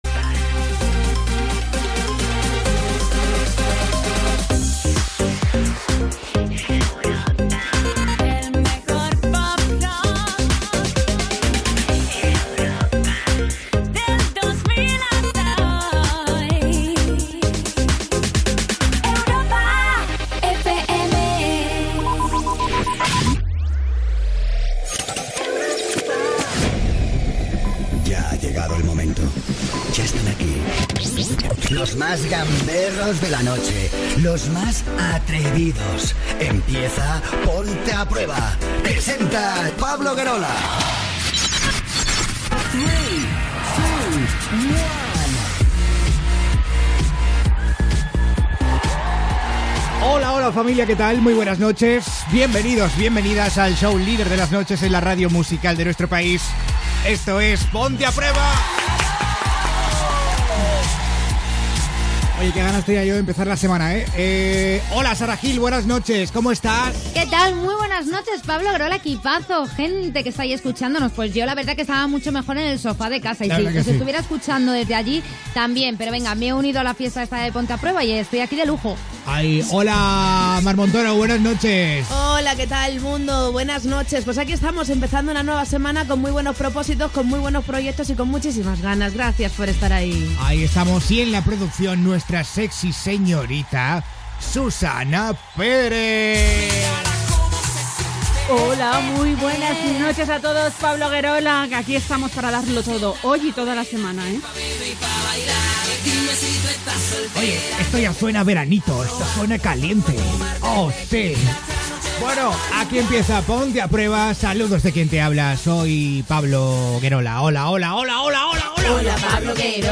Indicatiu de la ràdio, careta del programa, presentació, equip, indicatiu, formes de contactar amb el programa, missatges de l'audiència, tema musical, indicatiu, comentari sobre una influenciadora i la malatia de l'anorèxia, pregunta a l'audiència Gènere radiofònic Entreteniment